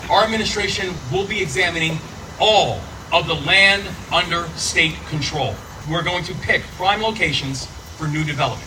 The Governor said state land will be used to help ease the housing crisis…